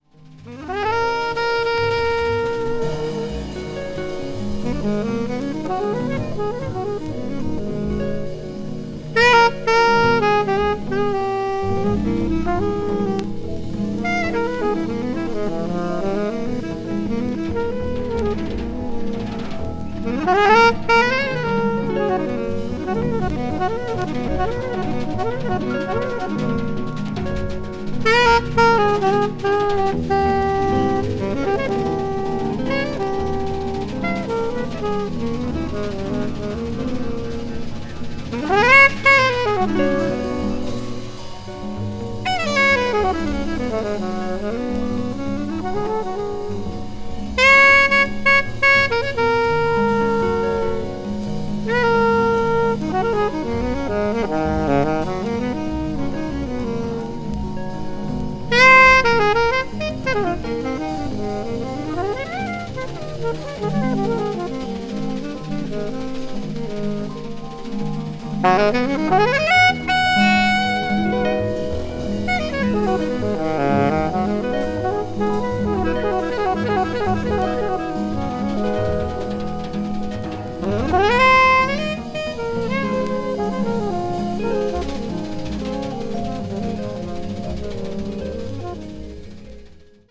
alto saxophonist
vibraphone
guitar
bass
drums
breathtakingly lyrical rendition